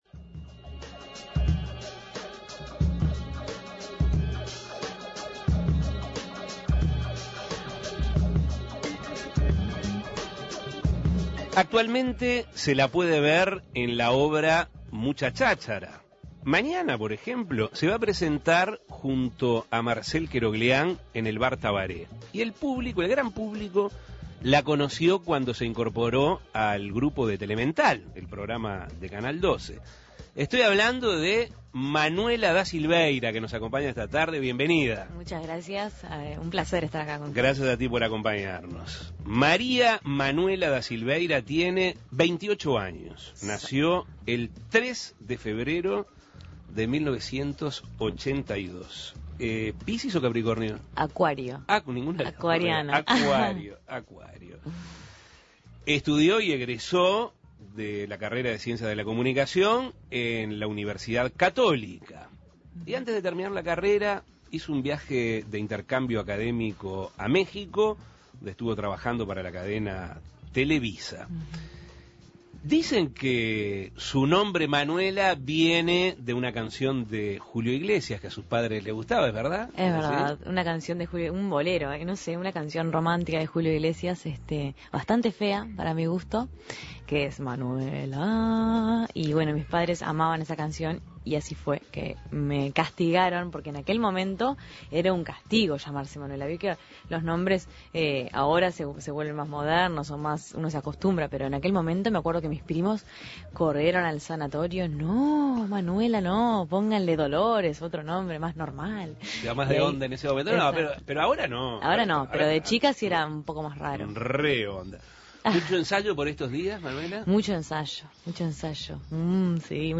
Estudió Ciencias de la Comunicación en la Universidad Católica y dialogó, entre otras cosas, sobre su carrera. Escuche la entrevista.